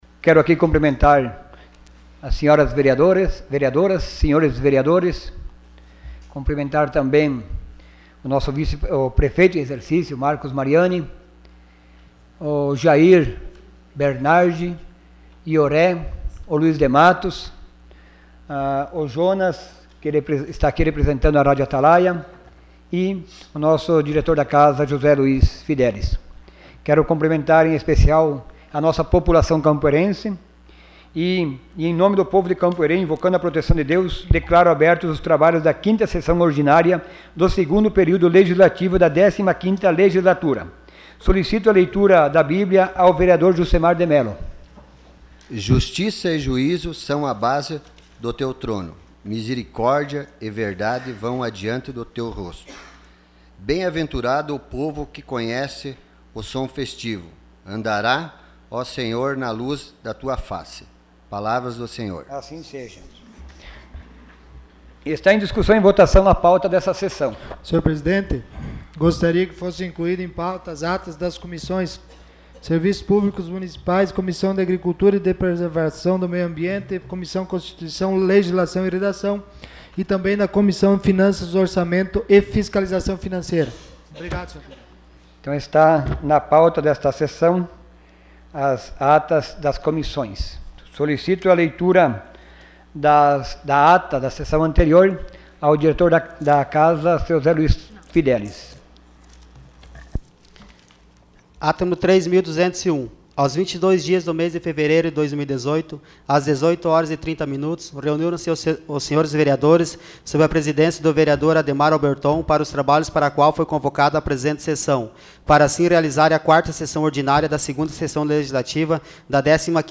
Sessão Ordinária dia 26 de fevereiro de 2018.